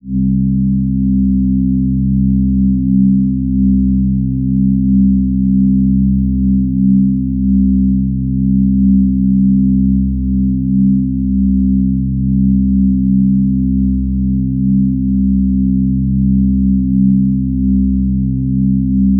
MB SO ICEY 2 BASS (11).wav